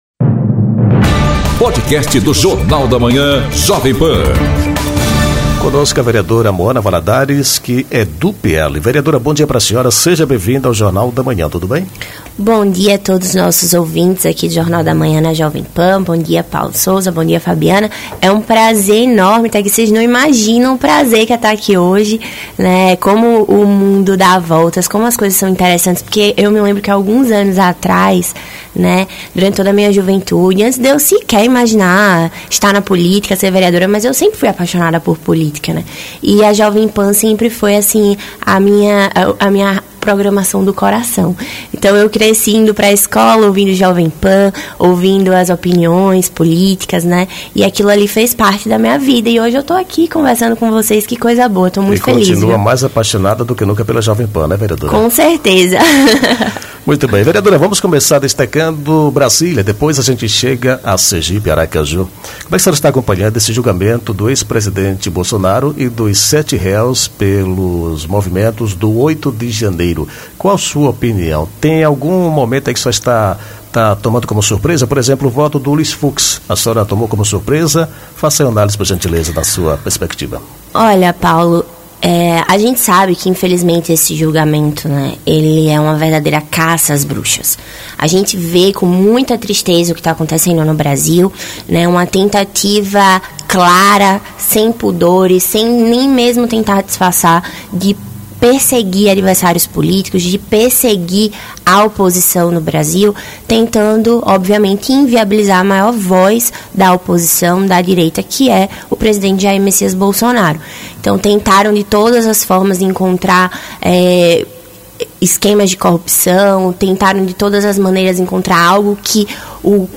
Entrevista com a vereadora Moana Valadares, que fala sobre a nova presidência do PL e os rumos do partido em 2026.